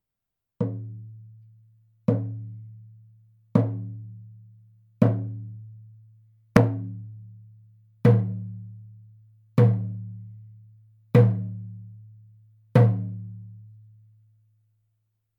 ネイティブ アメリカン（インディアン）ドラム NATIVE AMERICAN (INDIAN) DRUM 14インチ（elk アメリカアカシカ・ワピチ）
ネイティブアメリカン インディアン ドラムの音を聴く
乾いた張り気味の音です